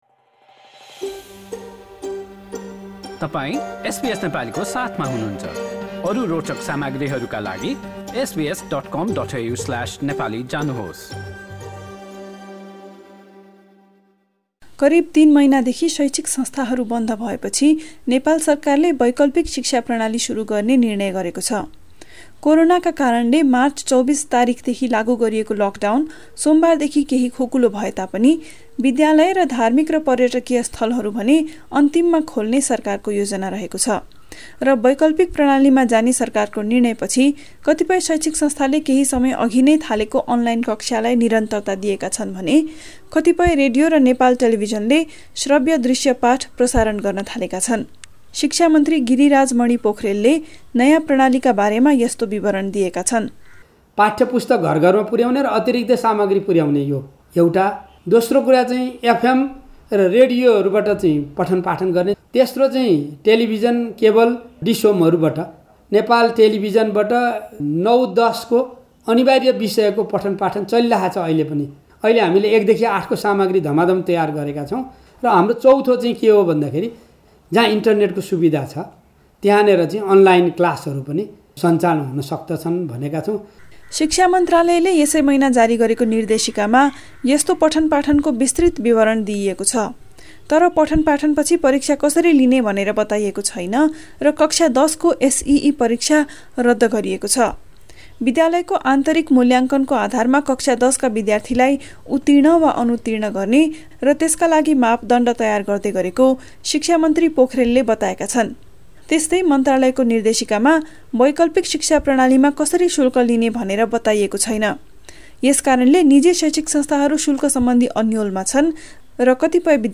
करिब तीन महिनादेखि शैक्षिक संस्थाहरू बन्द भएपछि नेपाल सरकारले वैकल्पिक शिक्षा प्रणाली सुरु गर्ने निर्णय गरेको छ। यसबारे एक रिपोर्ट।